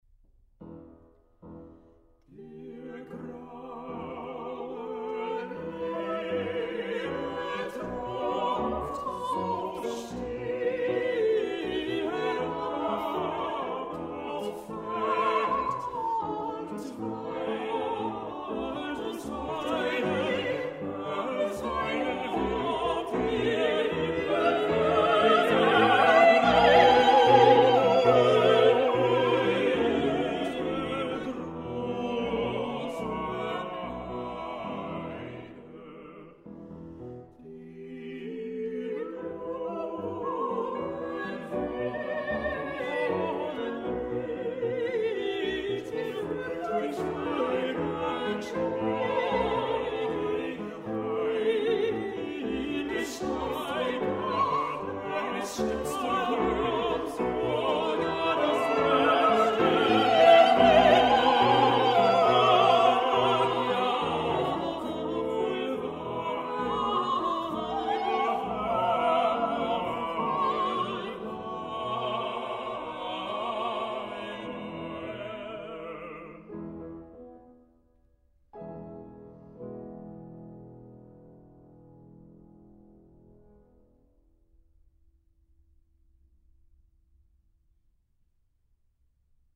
le répertoire du quatuor vocal romantique avec piano
quatuor vocal